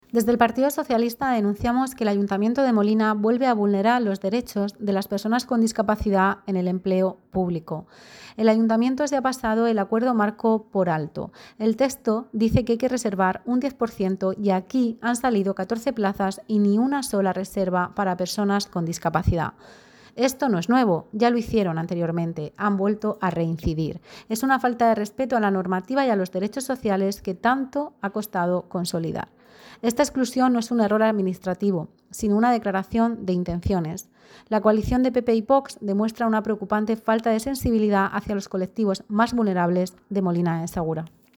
Declaraciones de Isabel Gadea